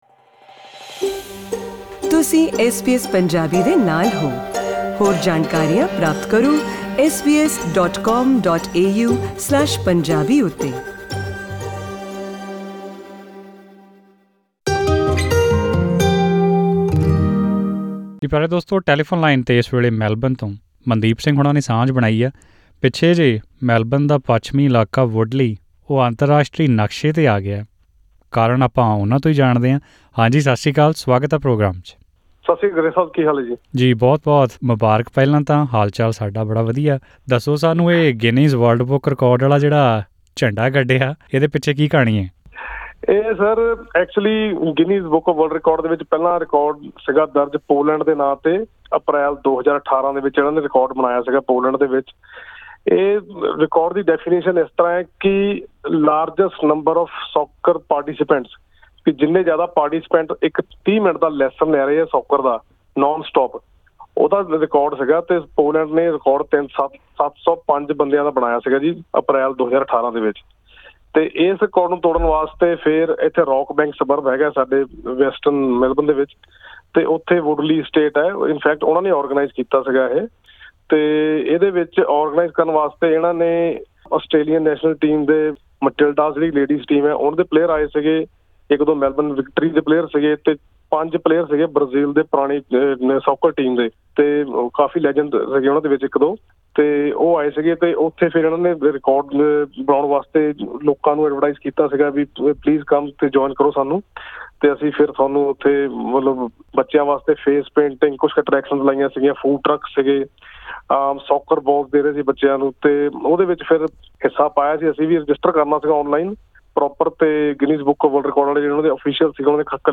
ਪੂਰੀ ਜਾਣਕਾਰੀ ਲਈ ਸੁਣੋ ਇਹ ਗੱਲਬਾਤ।